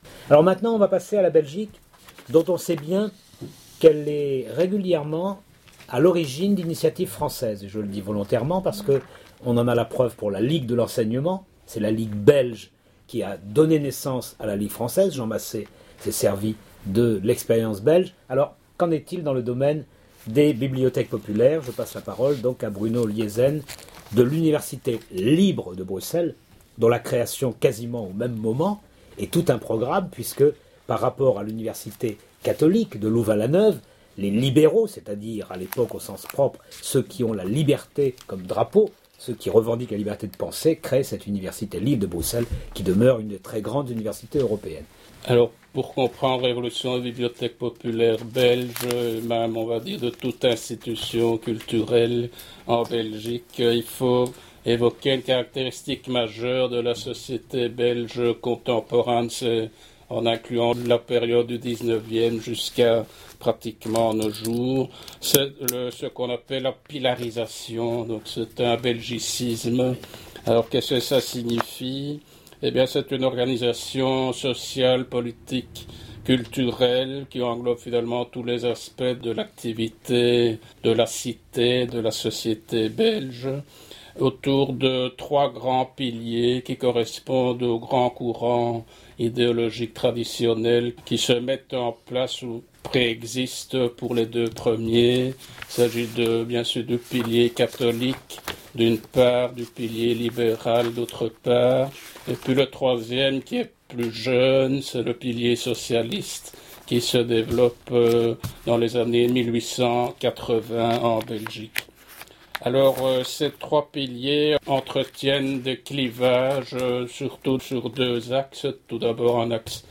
présente,dans le cadre de la table ronde organisée lors de la Journée Nomadede la mairie du IIIème arrondissement,